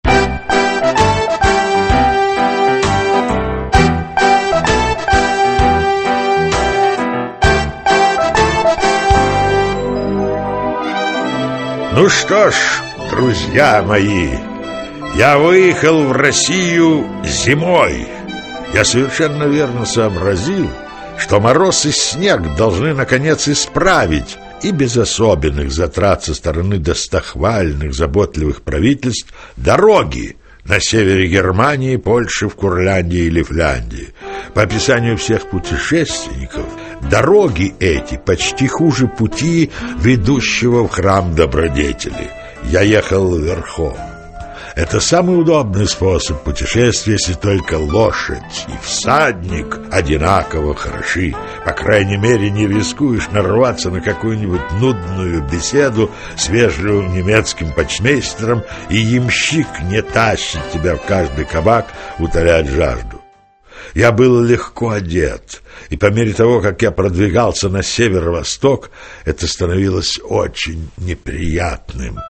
Аудиокнига Приключения барона Мюнхгаузена | Библиотека аудиокниг
Aудиокнига Приключения барона Мюнхгаузена Автор Рудольф Эрих Распе Читает аудиокнигу Зиновий Высоковский.